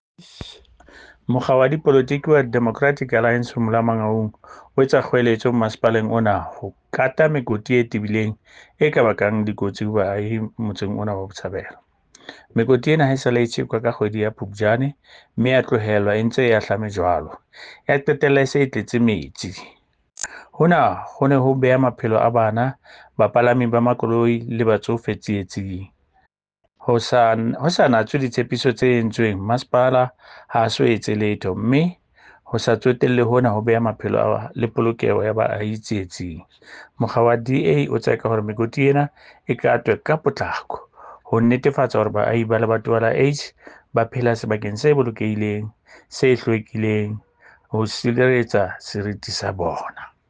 Sesotho soundbites by Cllr Edwin Maliela and